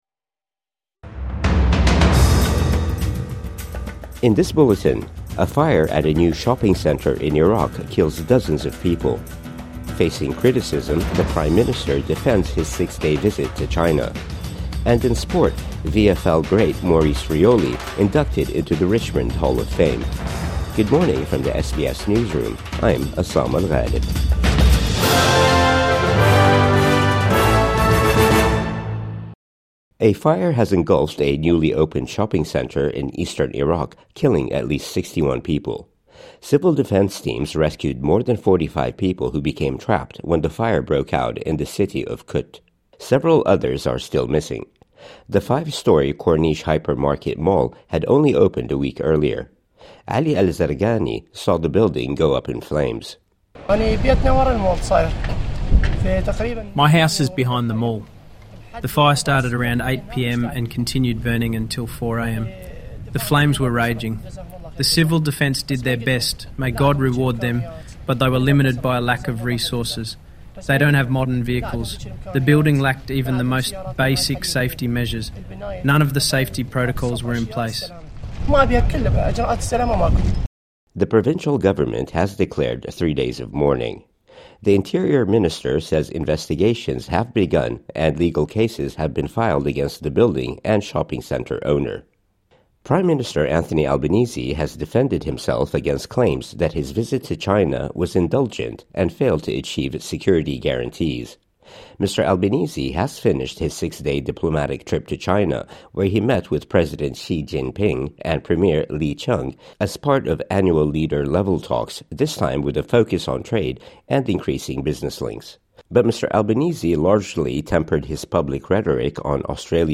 A fire at a new shopping centre in Iraq kills dozens of people | Morning News Bulletin 18 July 2025